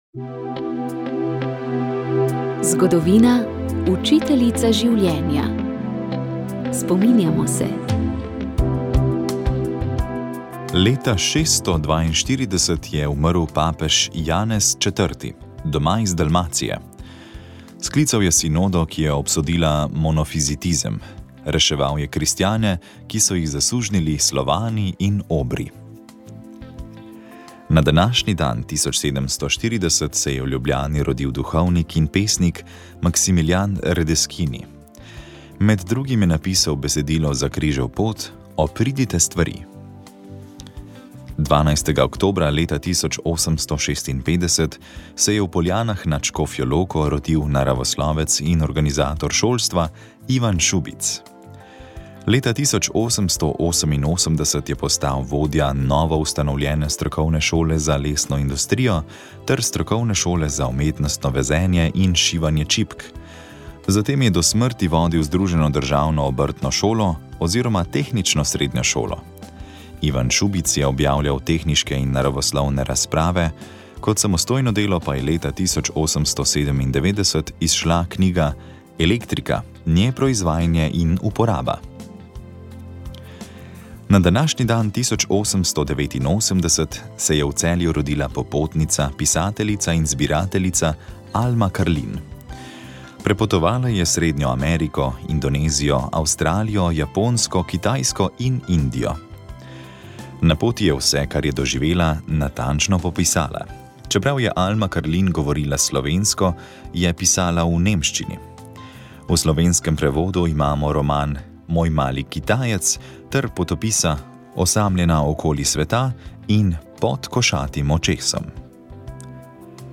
Sv. maša iz cerkve Marijinega oznanjenja na Tromostovju v Ljubljani 11. 10.